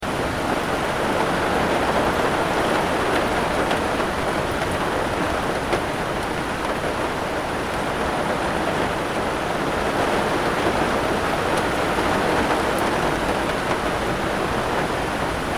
دانلود آهنگ باران 3 از افکت صوتی طبیعت و محیط
جلوه های صوتی
دانلود صدای باران 3 از ساعد نیوز با لینک مستقیم و کیفیت بالا